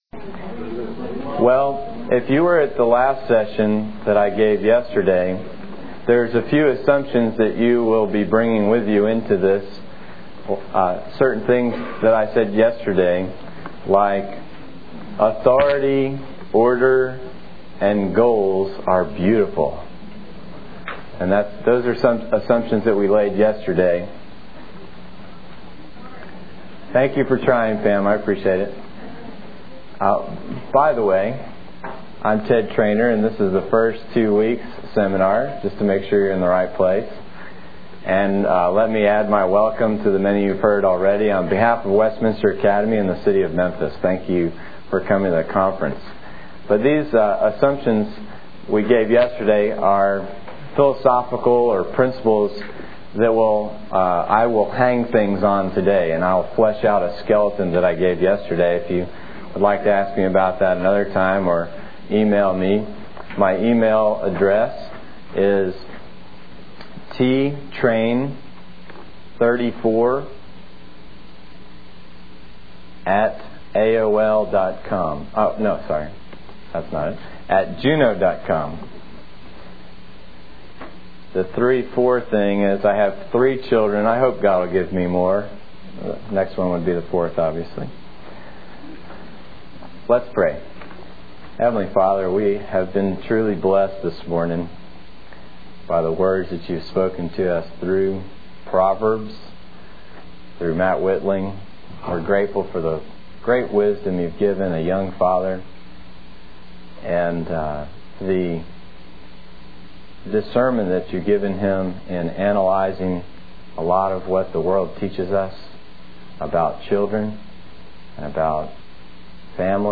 2001 Workshop Talk | 0:50:35 | All Grade Levels, Leadership & Strategic, General Classroom
The Association of Classical & Christian Schools presents Repairing the Ruins, the ACCS annual conference, copyright ACCS.